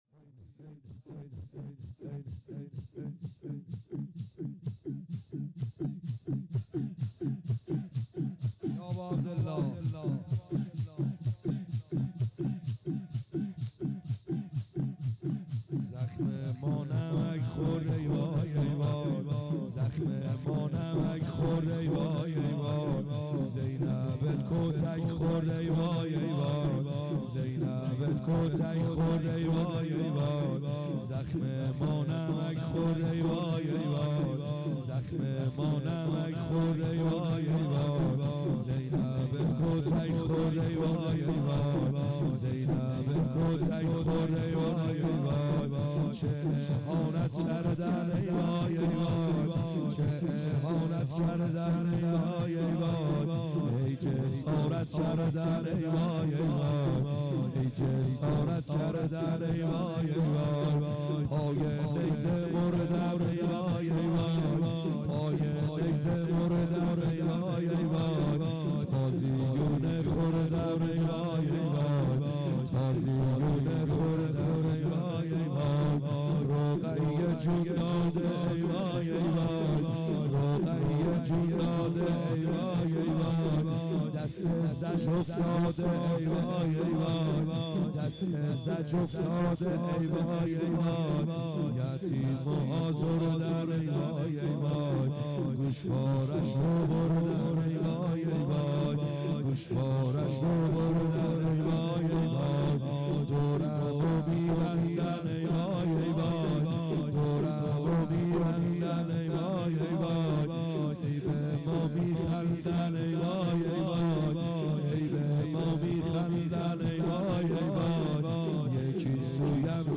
خیمه گاه - بیرق معظم محبین حضرت صاحب الزمان(عج) - لطمه زنی | لطفا با حال معنوی گوش کنید..
بیرق معظم محبین حضرت صاحب الزمان(عج)